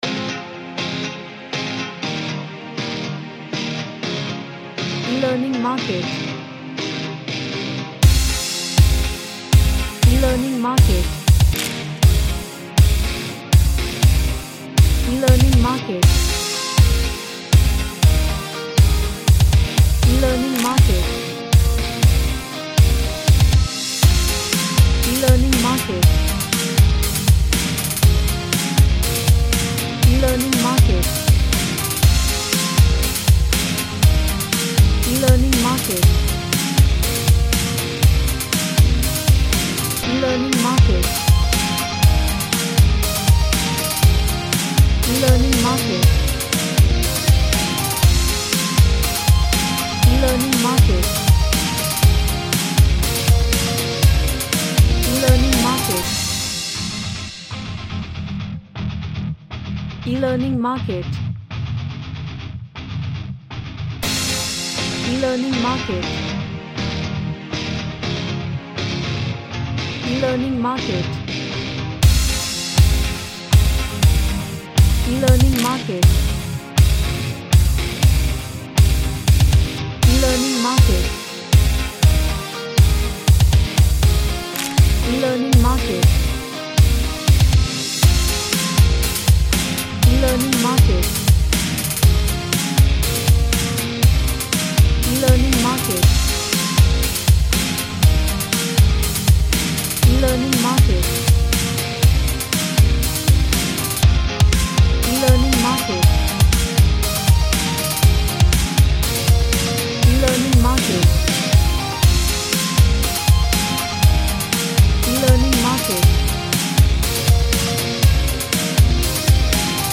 A Hardcore Rock Track with Action theme
Action / Sports